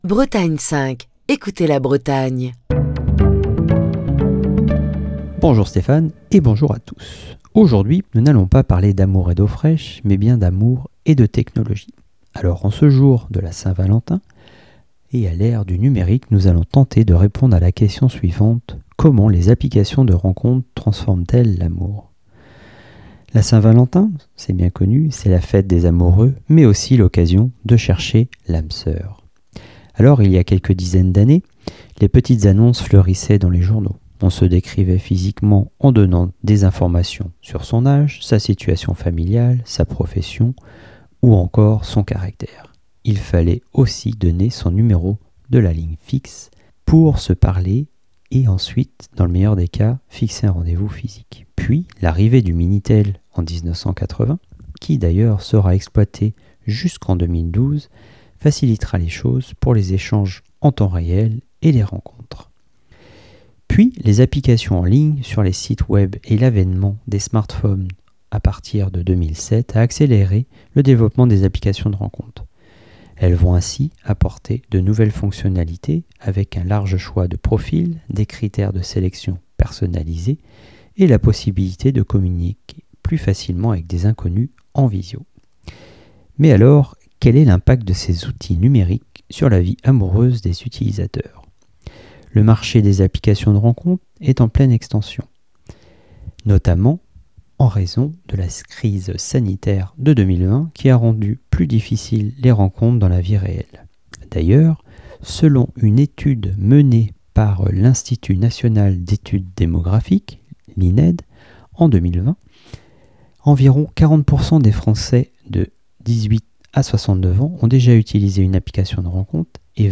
Chronique du 14 février 2024.